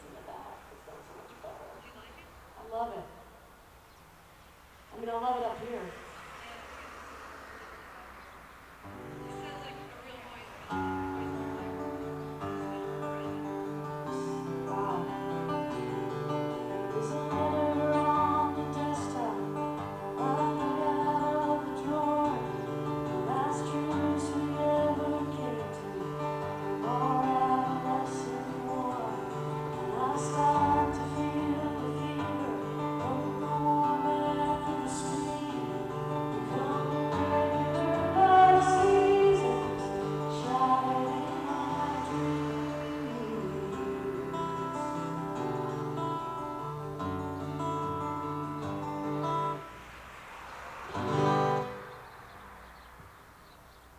(soundcheck)